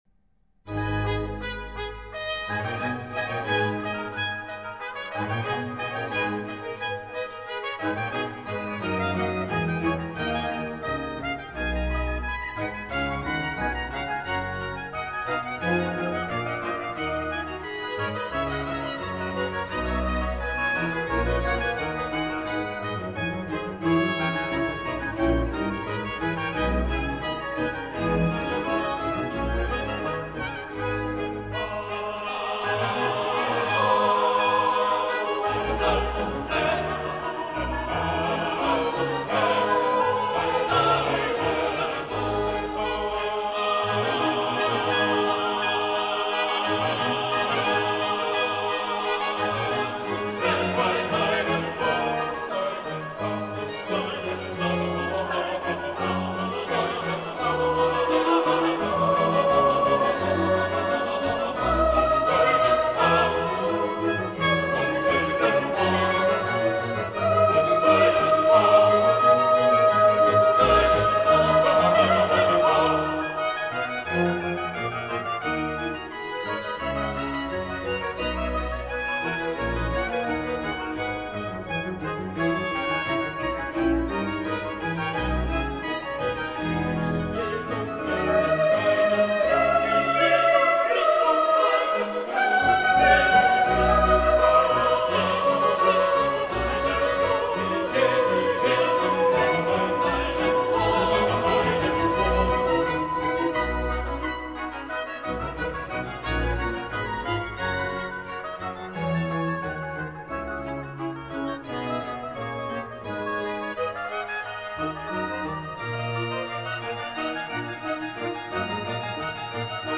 кантата бах (закрыта)